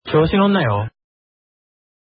SE (番組中に流れる効果音)